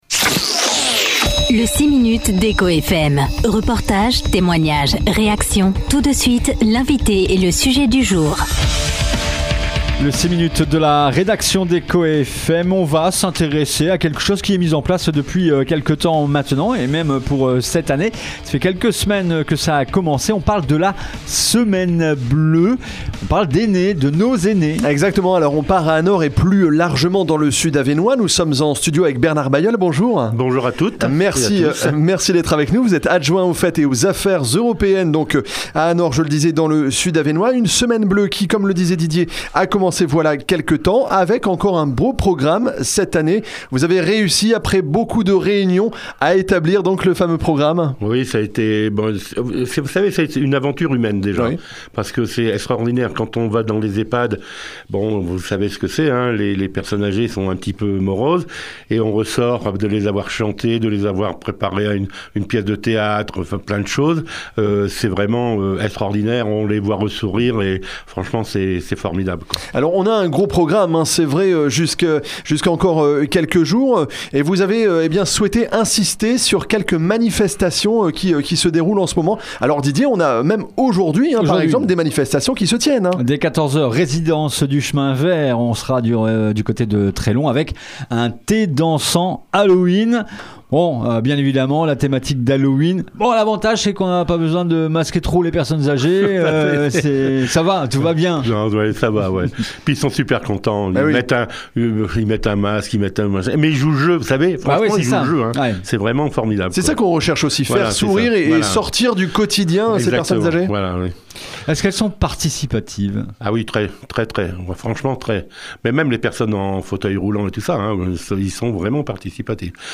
Bernard Bailleul, adjoint aux festivités et aux affaires européennes, était l’invité du 6 minutes ce mercredi 8 octobre sur ECHOFM